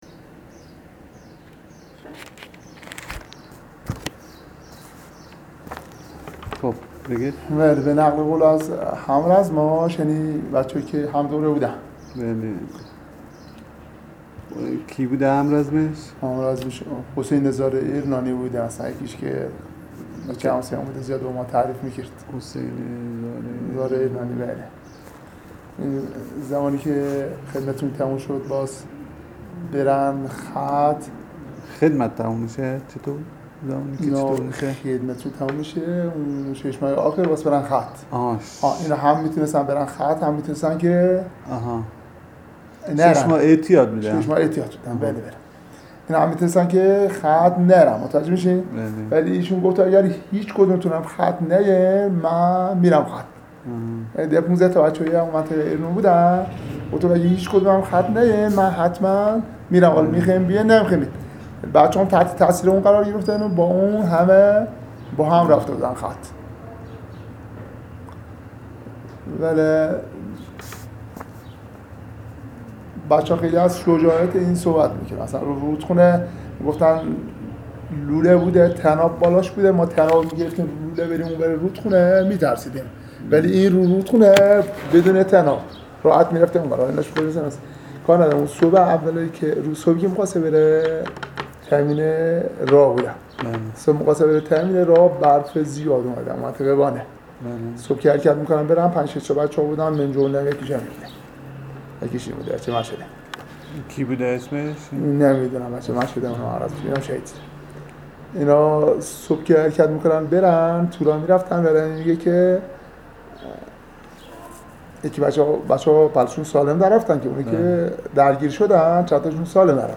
مصاحبه با خانواده